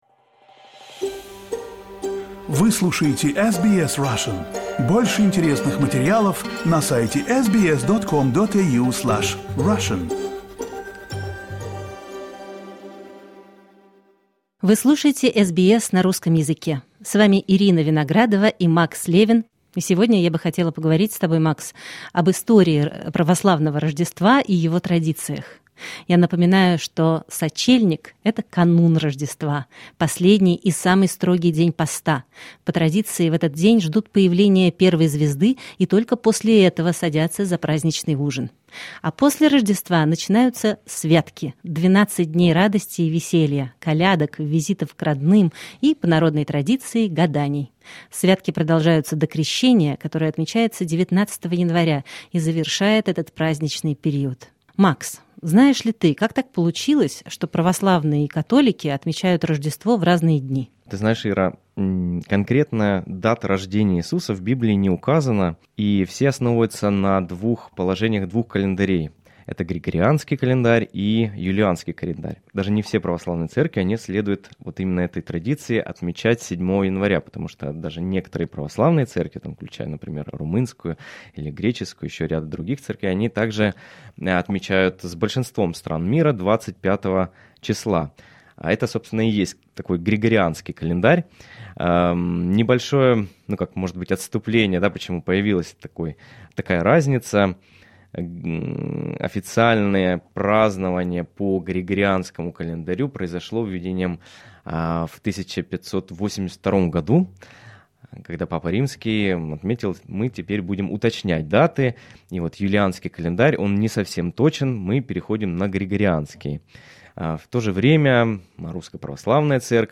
Ведущие вспоминают народные обычаи, святочные гадания и личный опыт празднования Православного Рождества, а также представляют архивный рассказ о том, как Православное Рождество отмечали в советской глубинке в середине XX века.